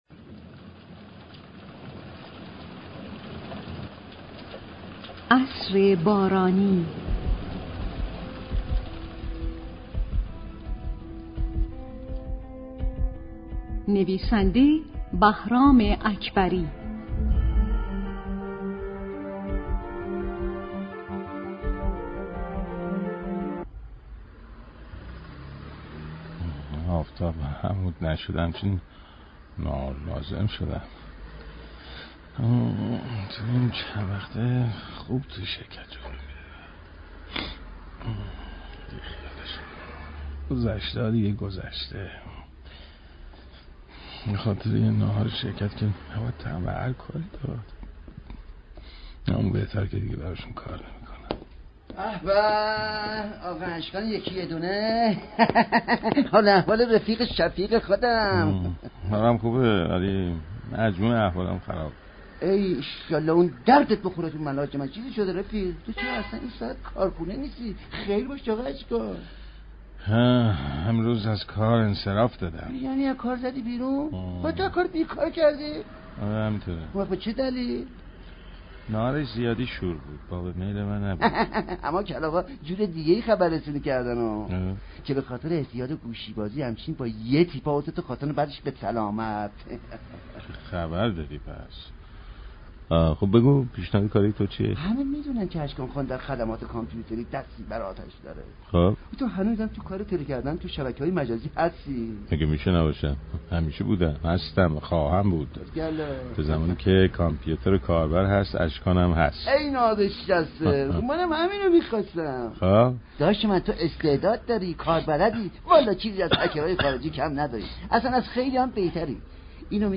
از سوم اسفند ماه ، دو سریال جدید رادیویی به روی آنتن رادیو نمایش می رود.